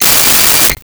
Cell Phone Ring 10
Cell Phone Ring 10.wav